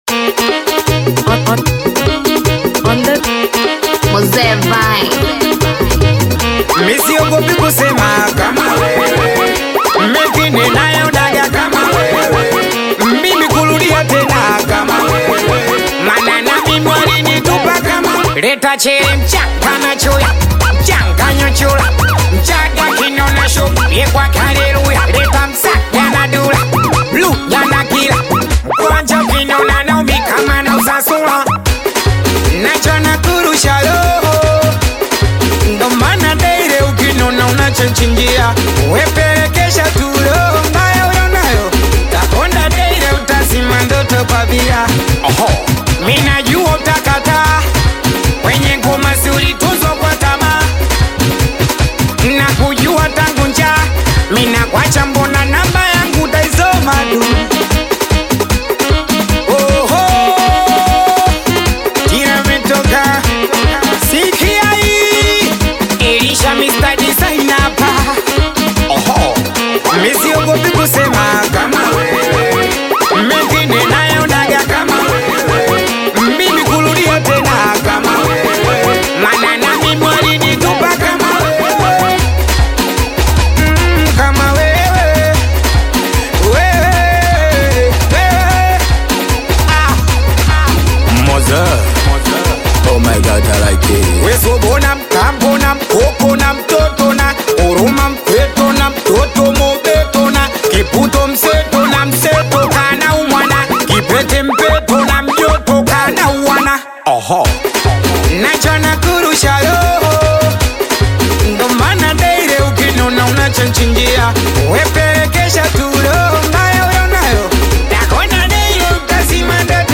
Singeli music track
Tanzanian Bongo Flava artist, singer, and songwriter
Singeli song